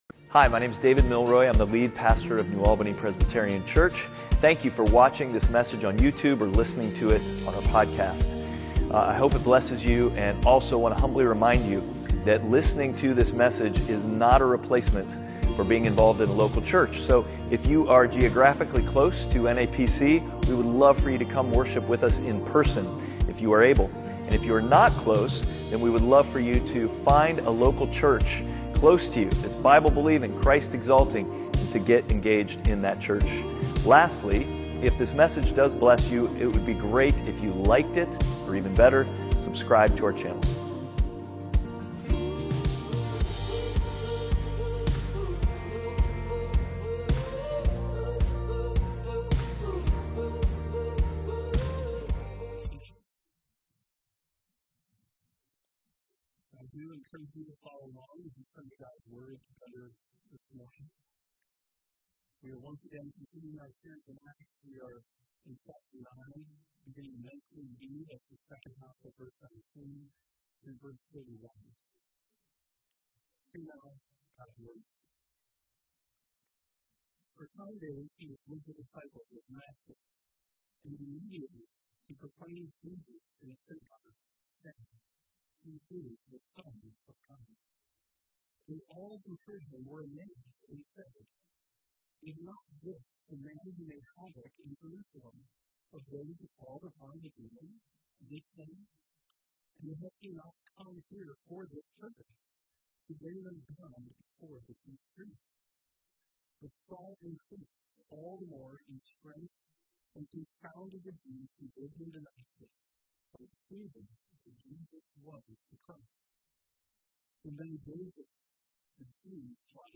Outward Passage: Acts 9:19b-31 Service Type: Sunday Worship « Outward